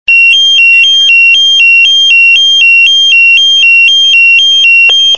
Alarmierung
Erst 1995 wurde dann ein Telefon in der Feuerwache Schnepfenthal installiert , und es kamen auch die ersten beiden Funkmeldeempfänger vom Typ Swissphone Memo .1997 wurde beschlossen alle Feuerwehren der Stadt Waltershausen mit Funkmeldeempfängern auszurüsten .